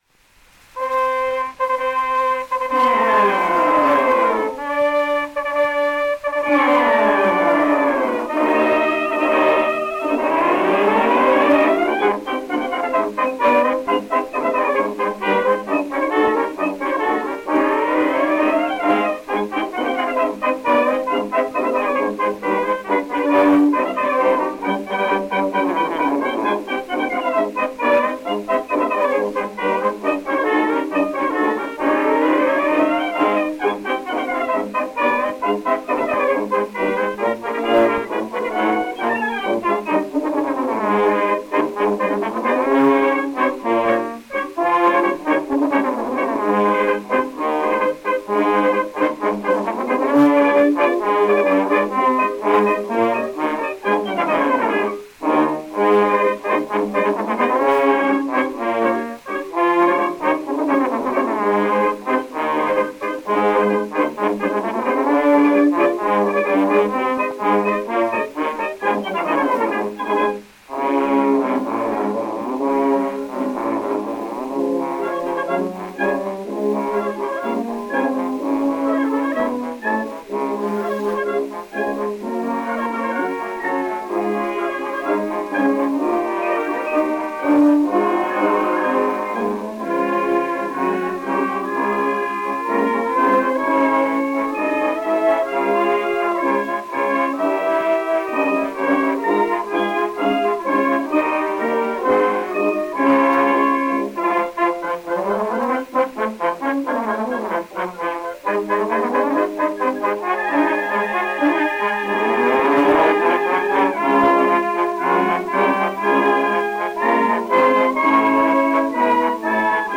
The Band of H. M. Coldstream Guards - Entry Of The Gladiators, 1905